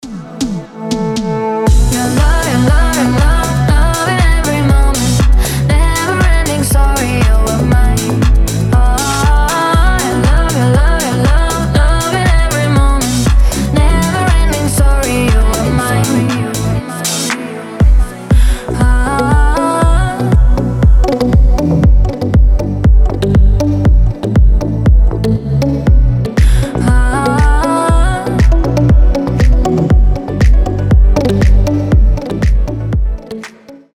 • Качество: 320, Stereo
красивые
женский вокал
deep house
dance